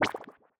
Babushka / audio / sfx / Battle / Enemies / SFX_Slime_Hit_V2_05.wav
SFX_Slime_Hit_V2_05.wav